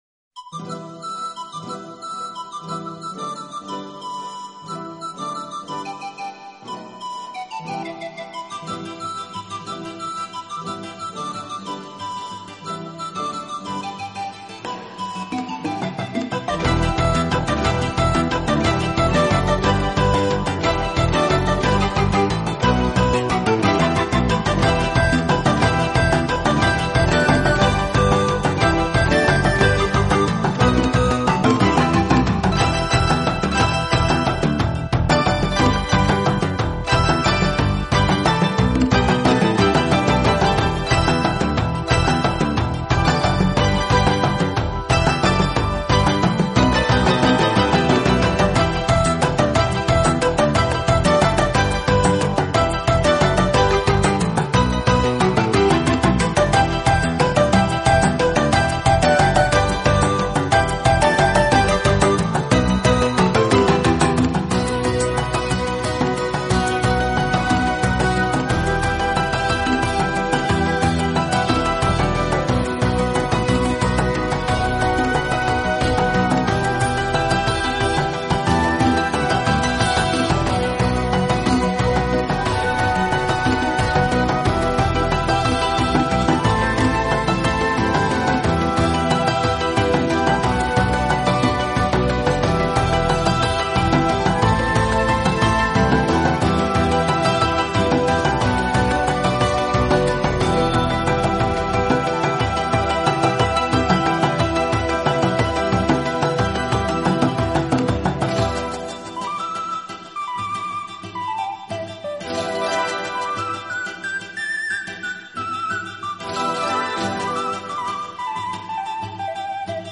音乐类型：NewAge 新世纪
音乐风格：New age
落音乐风格，而此类音乐的特点就是激荡，流畅，起伏跌宕，鼓，笛，排箫运用得很